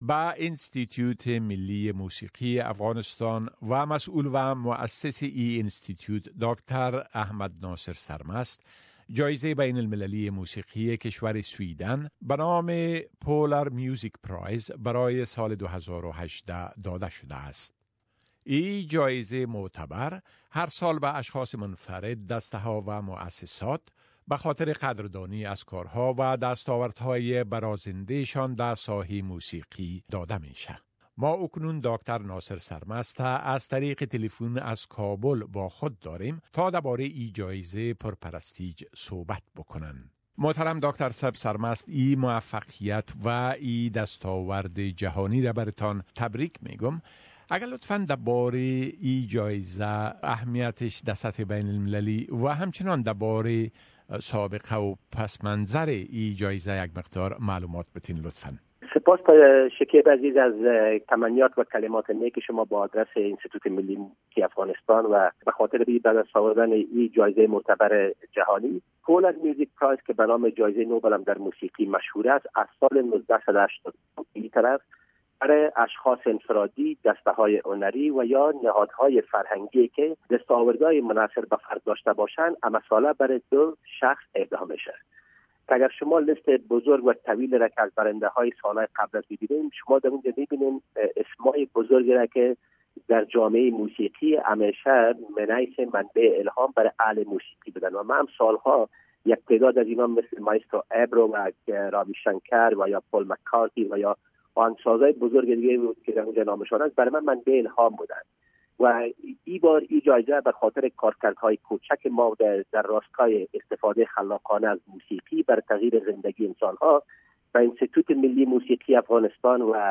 In an interview with SBS Dari, Dr Ahmad Naser Sarmast the director and founder of the Afghanistan National Institute of Music says the Polar Music Prize for 2018 which has been awarded to him and his institute is an achievement for the whole Afghan nation.